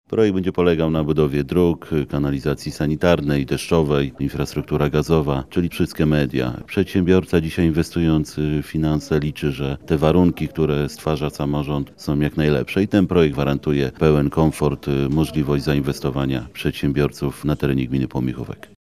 – Współczesnym przedsiębiorcom należy stwarzać jak najlepsze warunki – mówi wójt gminy Pomiechówek Dariusz Bielecki.